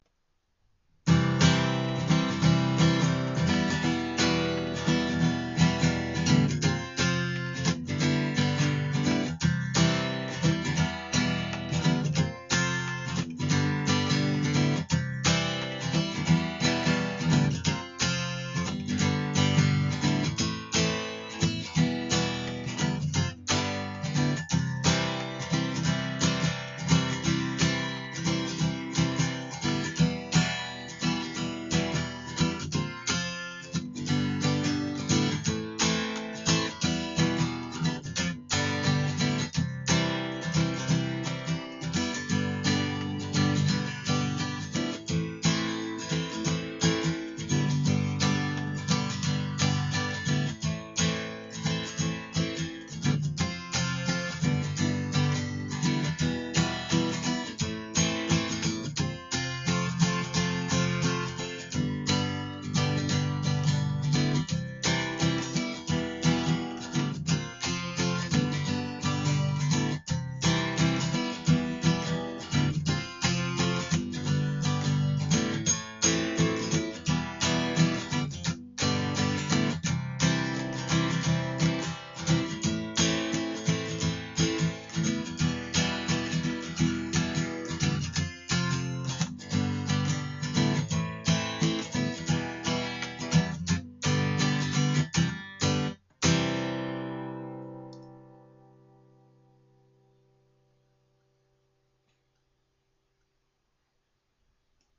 J-50のストローク音です。 ノートPCのマイクだとあんまり良さがでないなぁ。
低音がぶっといんですよ。
strokeJ-50.mp3